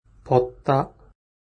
벗다ポッタ
벗다のネイティブの発音を沢山聞いて覚えましょう。
벗다の発音